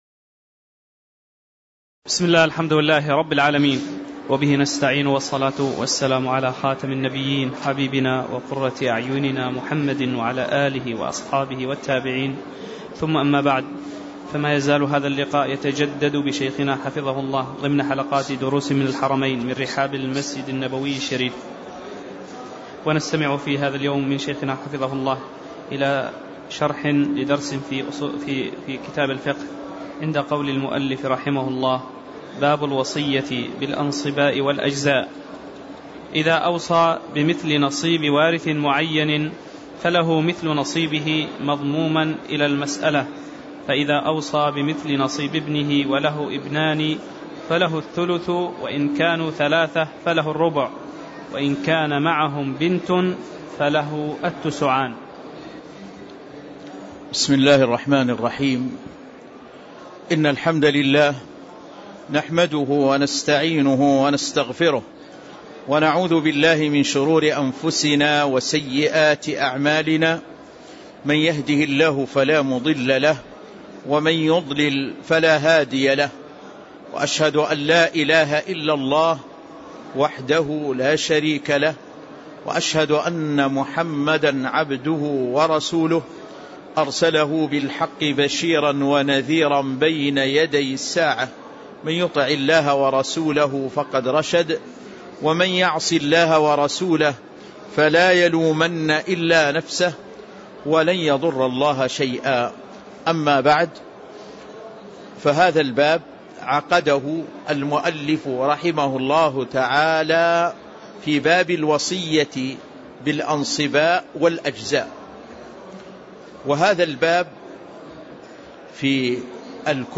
تاريخ النشر ٢١ ربيع الثاني ١٤٣٧ هـ المكان: المسجد النبوي الشيخ